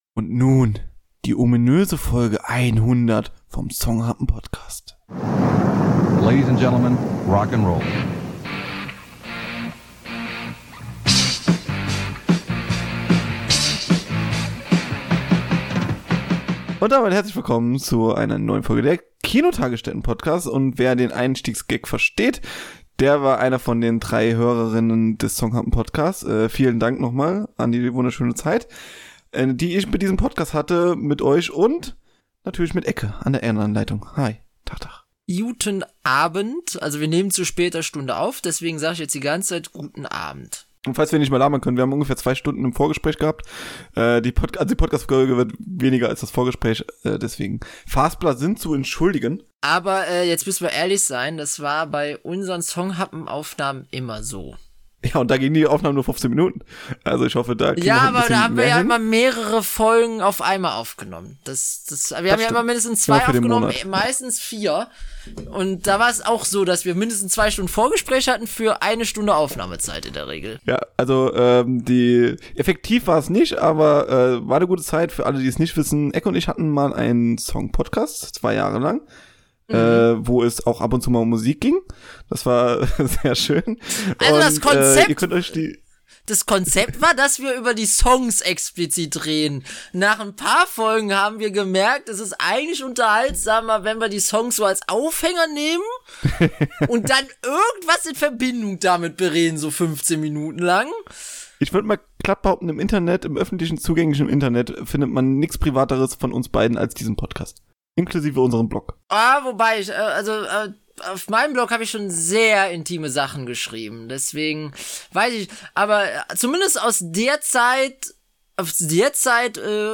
Musikvideos und MTV-Nostalgie | Special-Talk ~ Die Kinotagesstätte Podcast